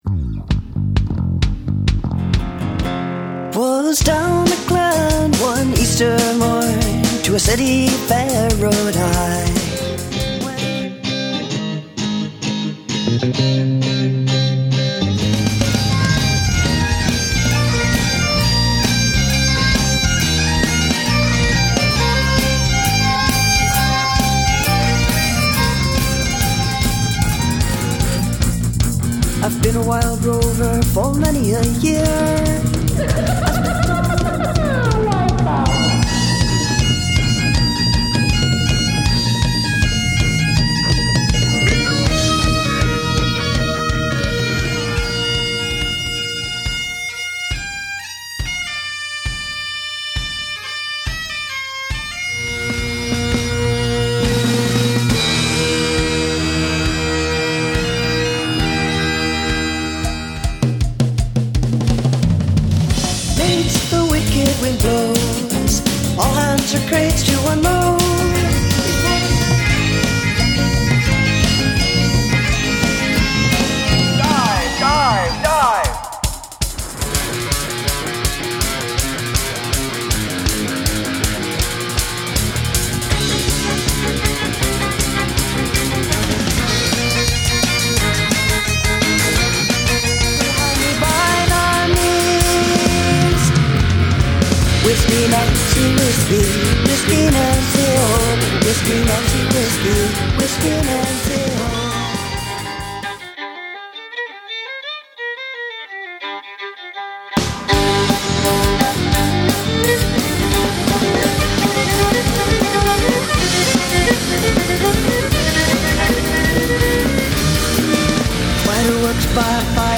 a rock group from Juneau.
whiskey spiced Irish and Scottish songs